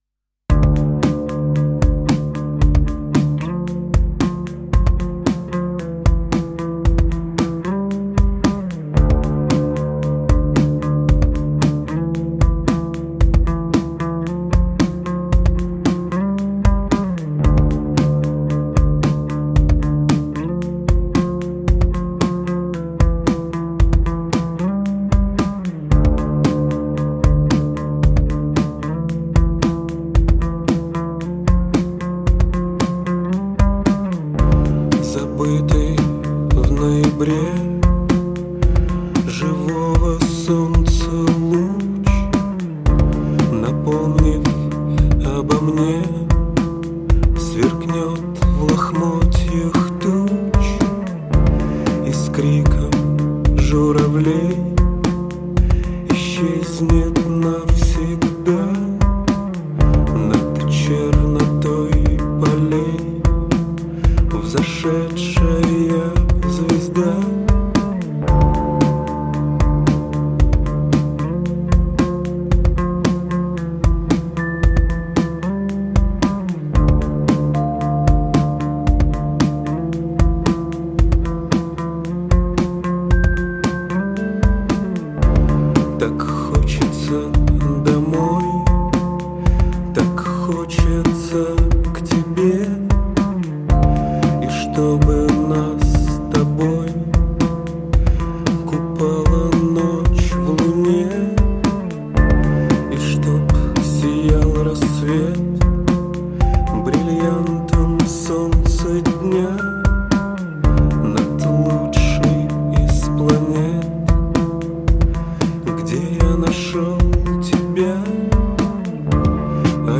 дельфин-крики.mp3